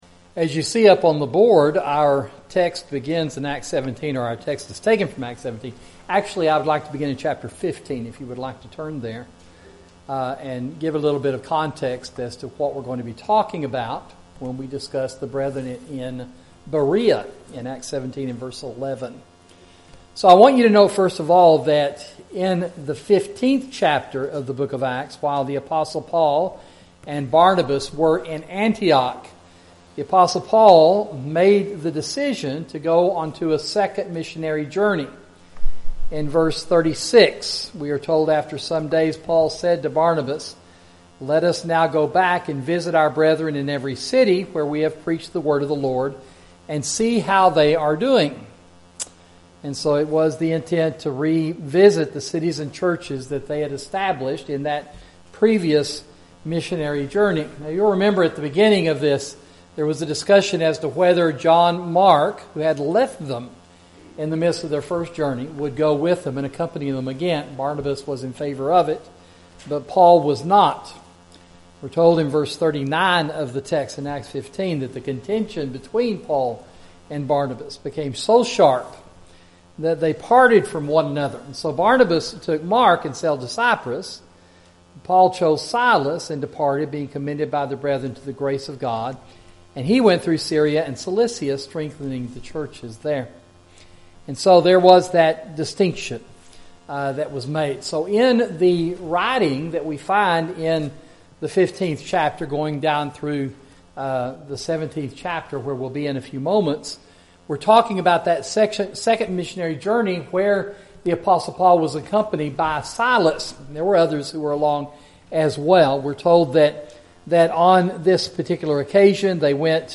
Sermon: Fair-Minded Toward God – Sound Teaching